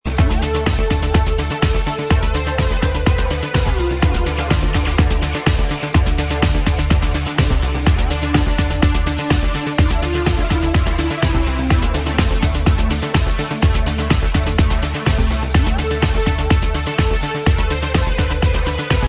New progressive track